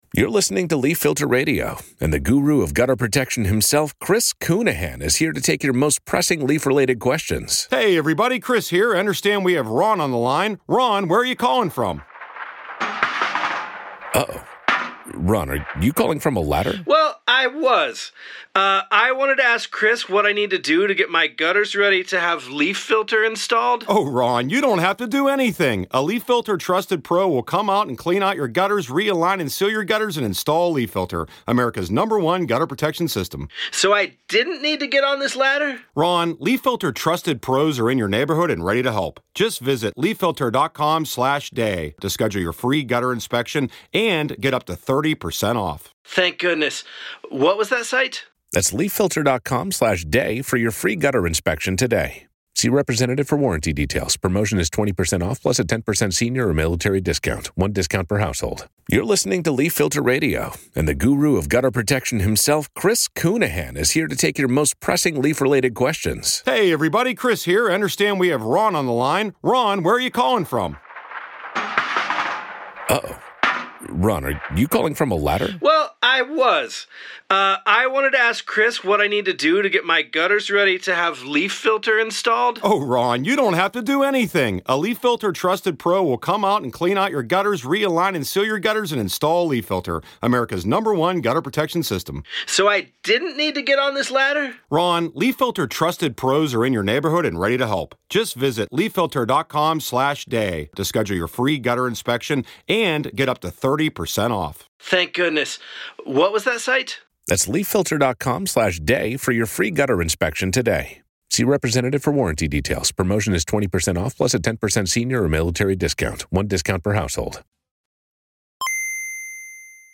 We Hate Movies WHM Entertainment Tv & Film, Comedy 4.7 • 4.9K Ratings 🗓 5 July 2023 ⏱ 70 minutes 🔗 Recording | iTunes | RSS 🧾 Download transcript Summary On this week's On-Screen Live , the guys take a quick break from the long holiday weekend to go over the weekend box office, react to trailers like the one for the new Liam Neeson sit-down action movie, Retribution , chat about current releases like No Hard Feelings , FX's The Bear , and one of the summer's most anticipated films, Indiana Jones and the Dial of Destiny ! This is of course the audio-only edition of On-Screen Live , if you want the full experience, check out the show on our YouTube channel.